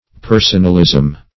Meaning of personalism. personalism synonyms, pronunciation, spelling and more from Free Dictionary.
Search Result for " personalism" : The Collaborative International Dictionary of English v.0.48: Personalism \Per"son*al*ism\, n. The quality or state of being personal; personality.